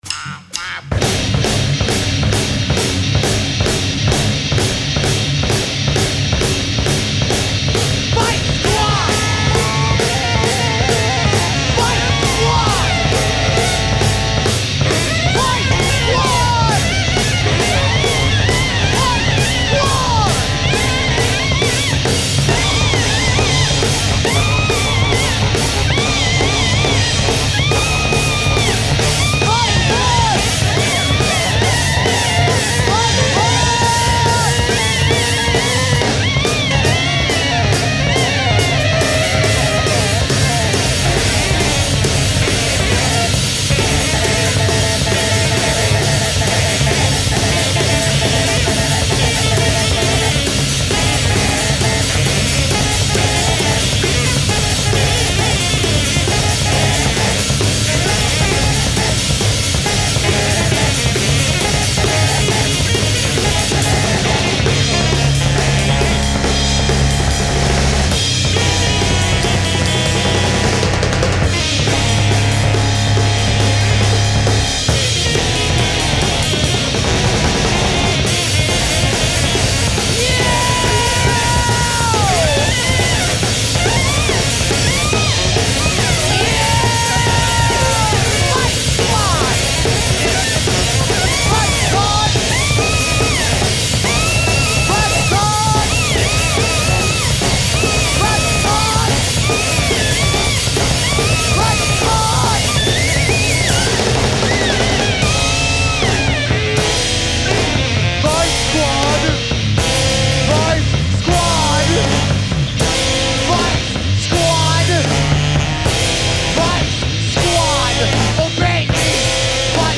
Recorded live at the 39th Street loft in Brooklyn.
alto saxophone, fx
tenor saxophone
Stereo (Metric Halo / Pro Tools)